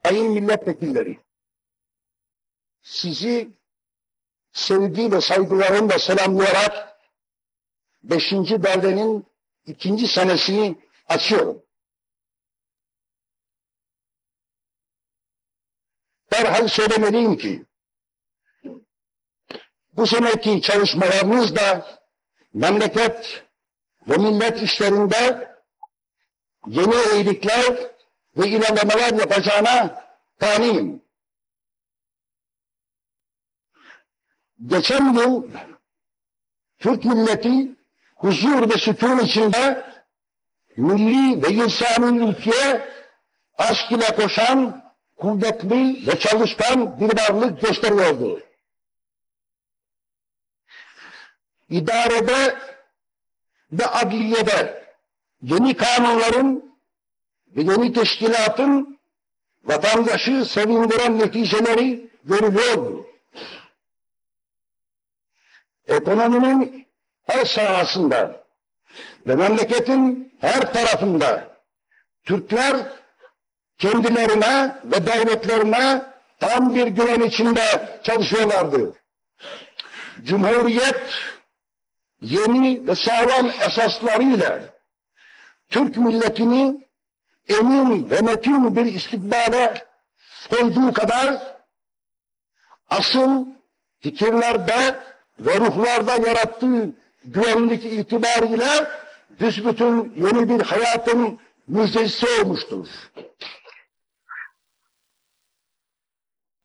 ataturk_voice_restorated